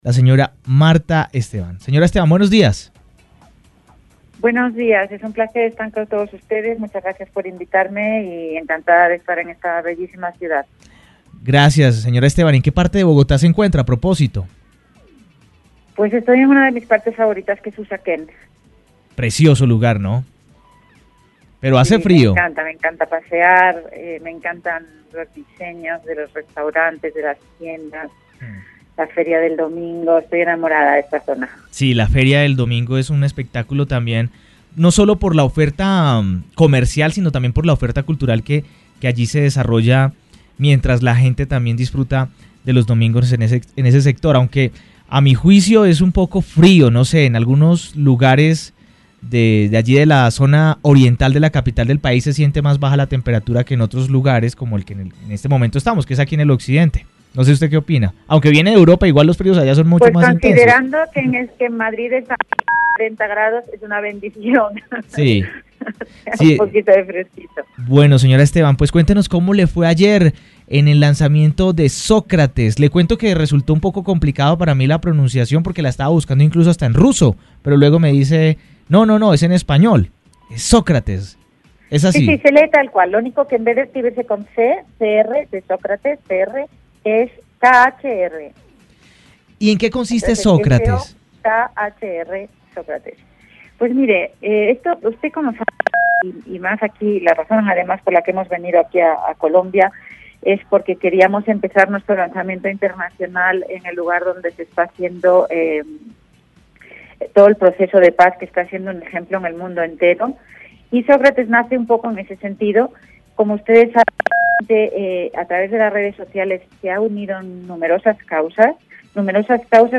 En diálogo con UNIMINUTO Radio